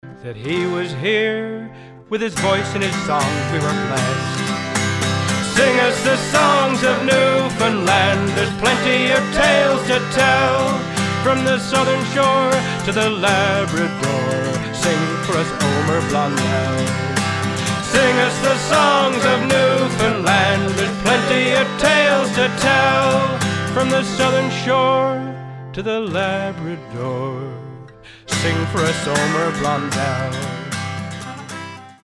- historical piece, Newfoundland style waltz